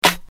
Snares
nt - bsr snare 1.wav